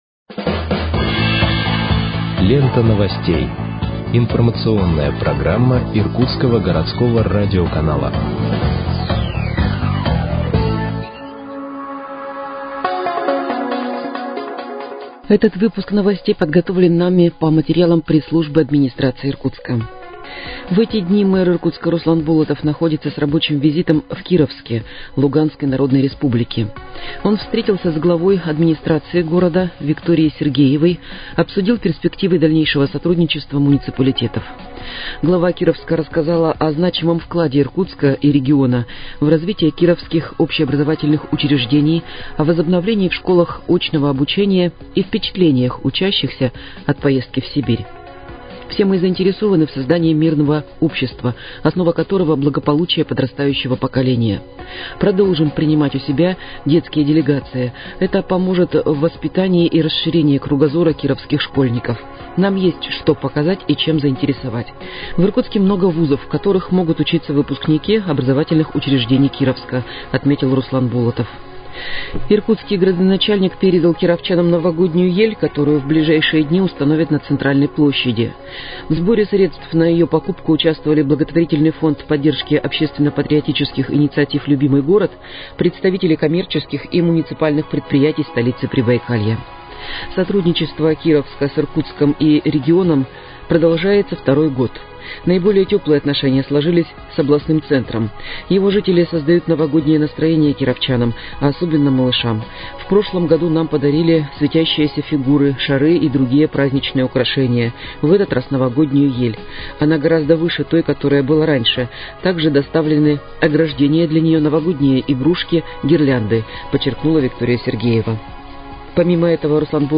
Выпуск новостей в подкастах газеты «Иркутск» от 01.12.2023 № 1